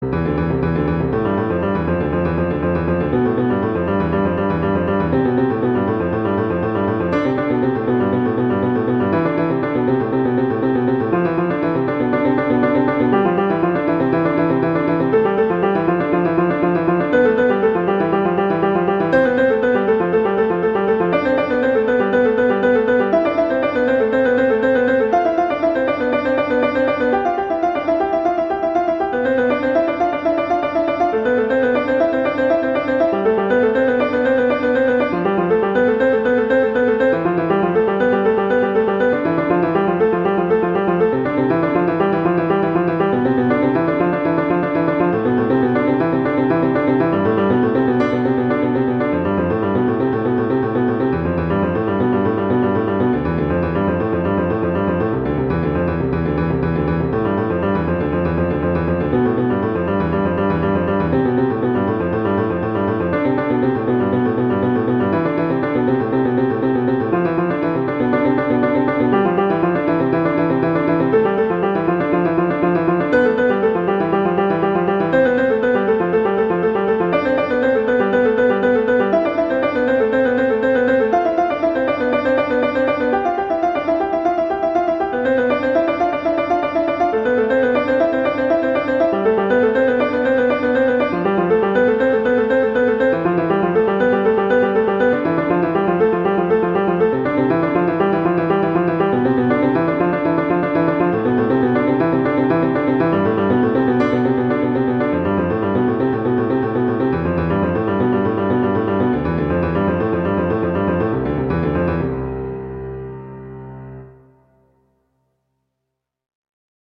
classical, french, instructional, children
C major
♩=80-120 BPM